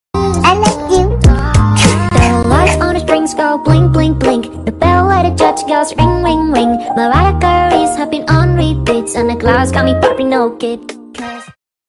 You Just Search Sound Effects And Download. tiktok hahaha sound effect Download Sound Effect Home